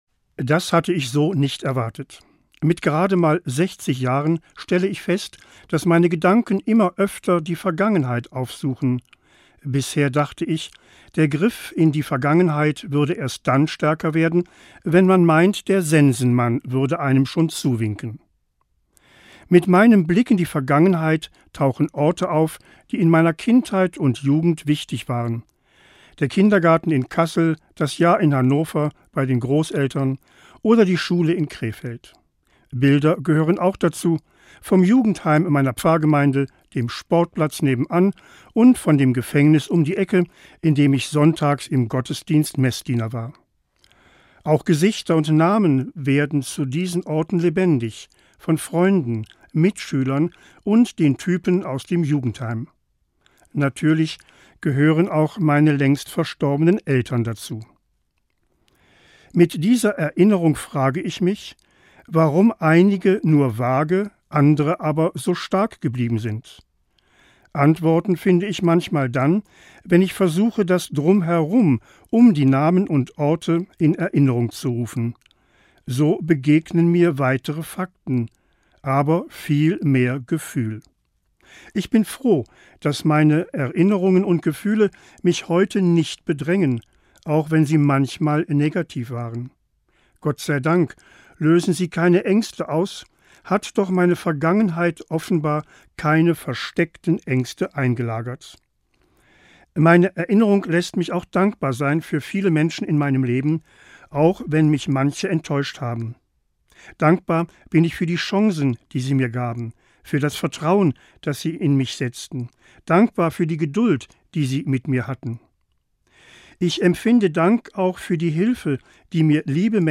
Morgenandacht 8.10.